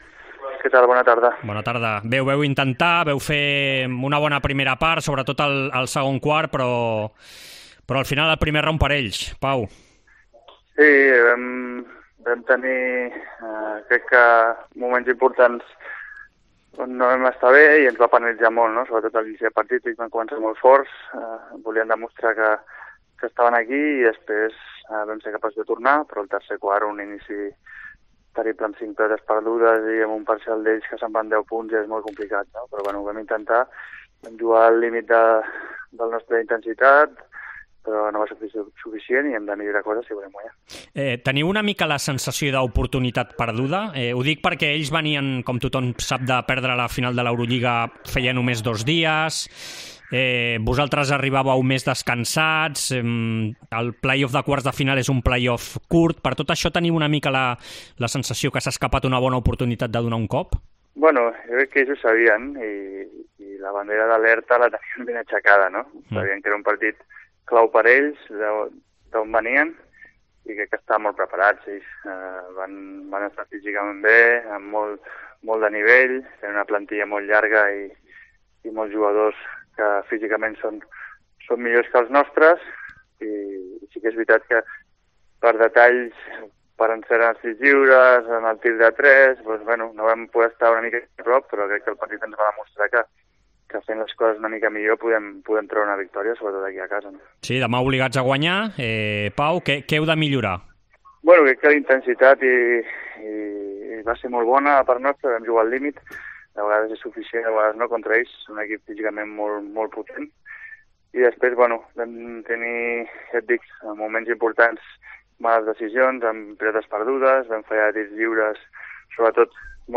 El jugador de la Penya y exBarça, Pau Ribas, ha pasado por los micrófonos de Esports COPE donde ha analizado el playoff contra los azulgranas tras perder ayer el primer partido por 84 a 74.